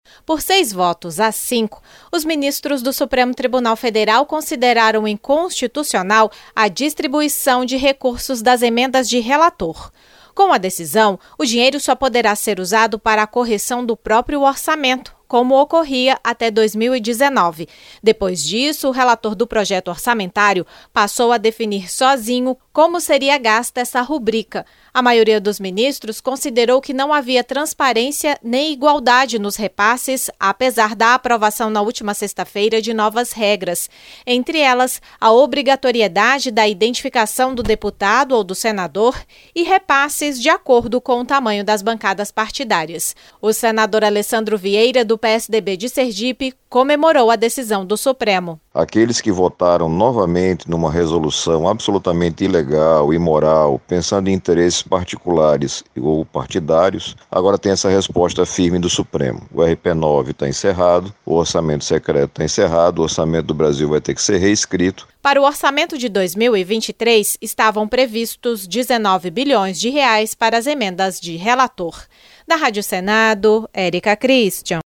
O senador Alessandro Vieira (PSDB-SE) comemorou a decisão ao afirmar que as chamadas RP-9 apenas atendiam a interesses partidários ou particulares.